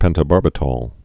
(pĕntə-bärbĭ-tôl, -tăl)